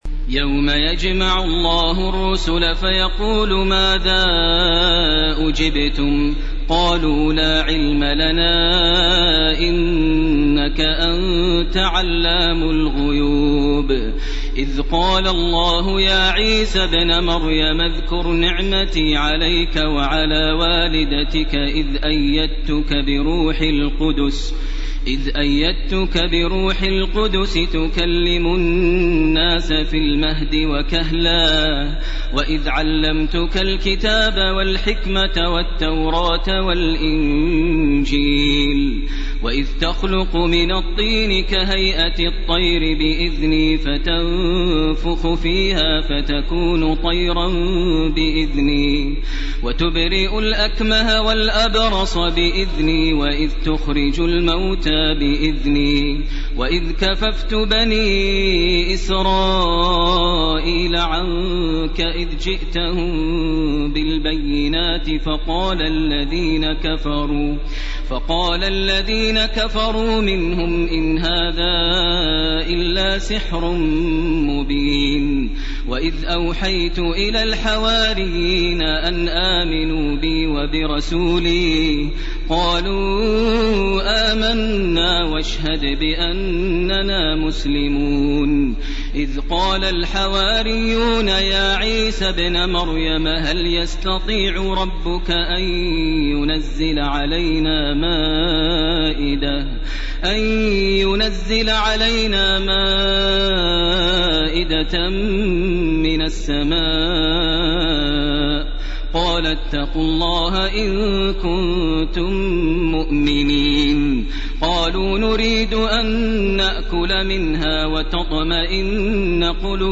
سورة المائدة 109 الي اخرها سورة الأنعام من 1 - 36 > تراويح ١٤٢٩ > التراويح - تلاوات ماهر المعيقلي